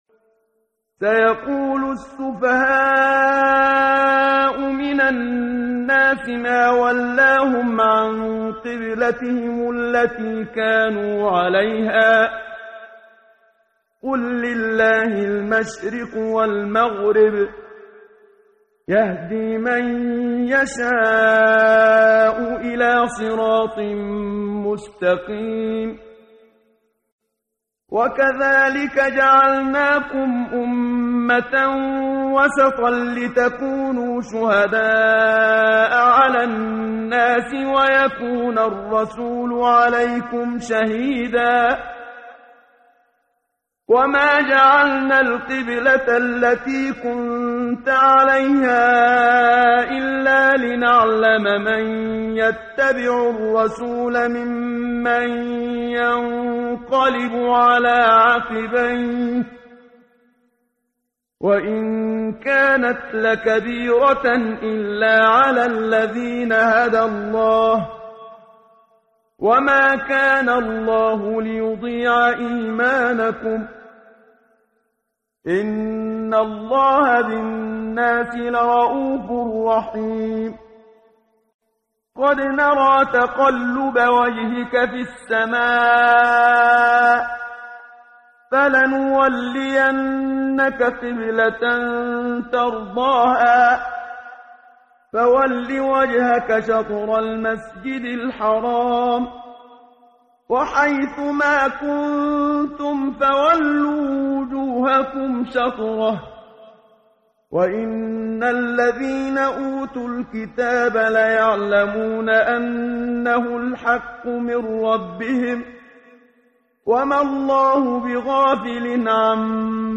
ترتیل صفحه 22 سوره مبارکه بقره (جزء دوم) از سری مجموعه صفحه ای از نور با صدای استاد محمد صدیق منشاوی